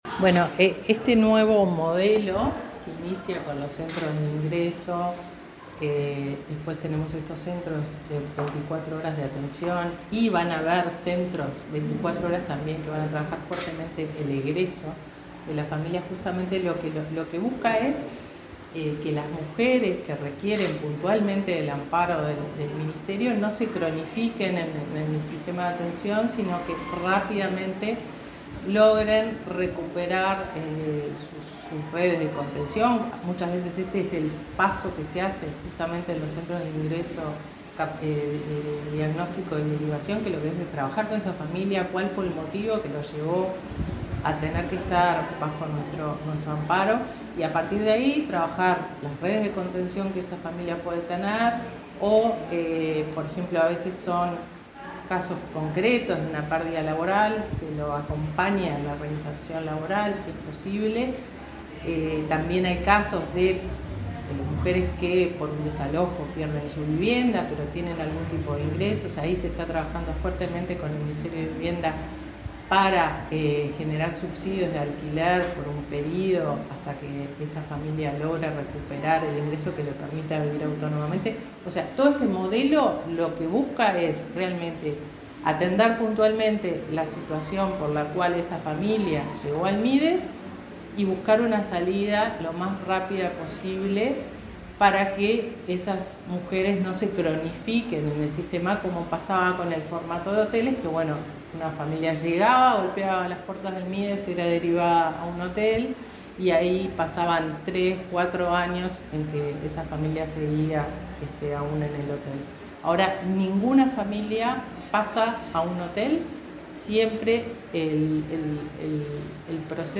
Entrevista a la directora nacional de Protección Social del Mides, Fernanda Auersperg